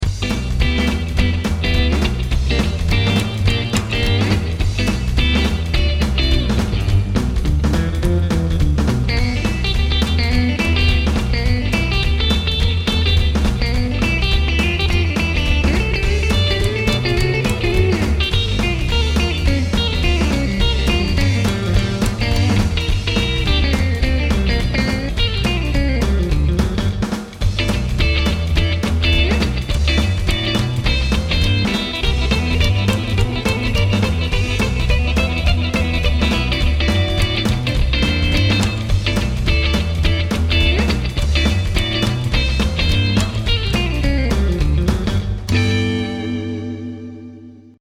ROCK Y MAS ROCK